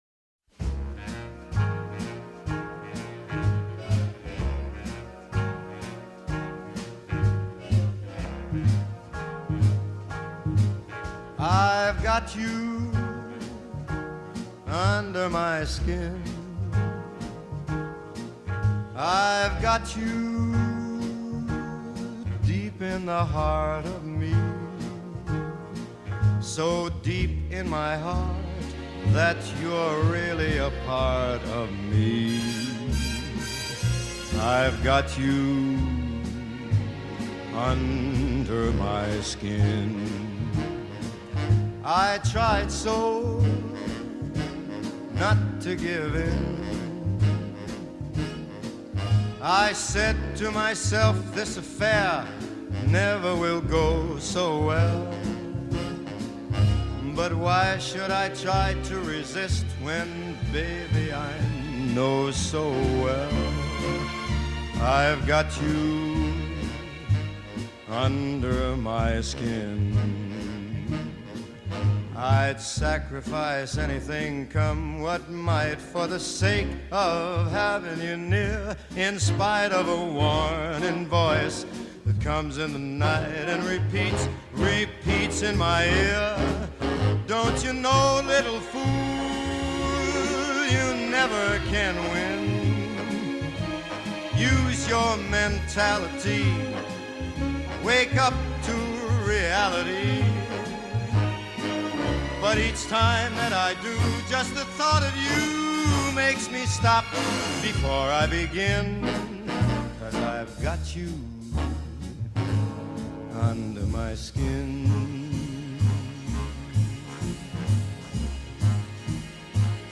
Jazz Vocal, Traditional Pop, Swing